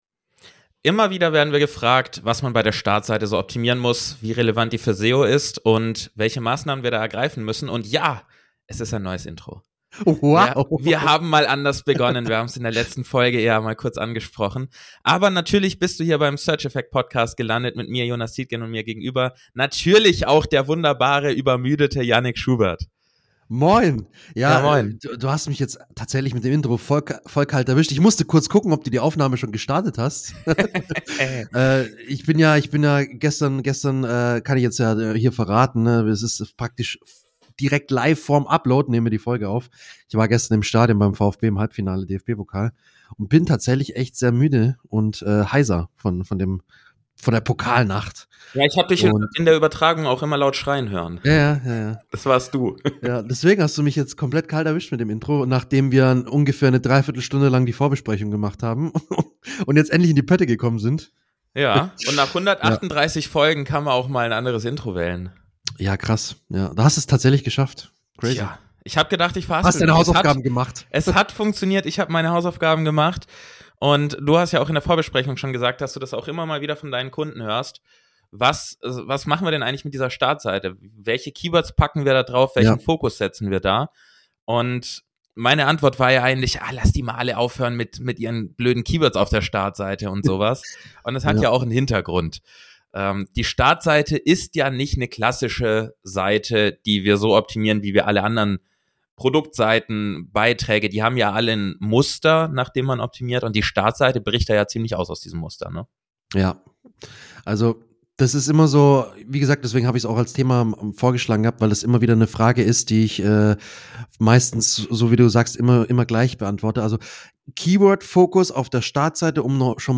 (Entschuldige das abrupte Ende, da hat uns die Technik im Stich gelassen) Mehr